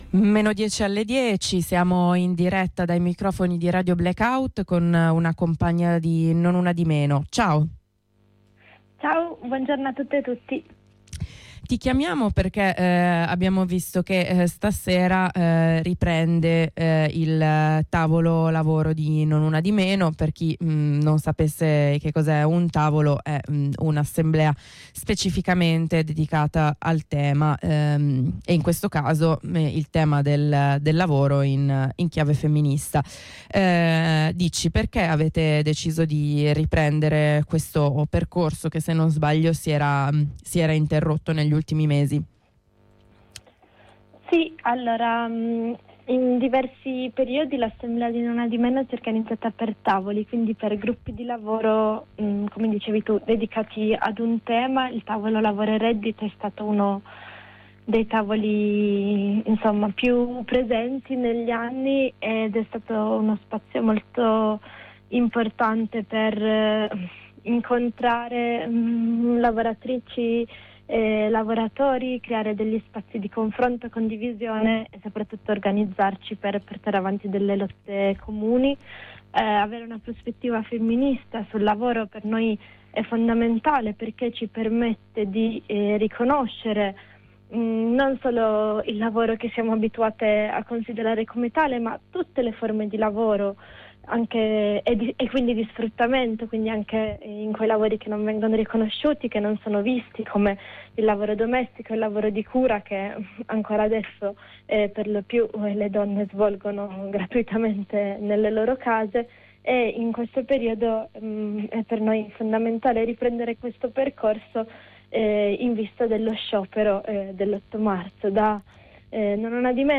Ne parliamo con una compagna di Non Una di Meno Torino: